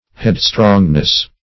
Headstrongness \Head"strong`ness\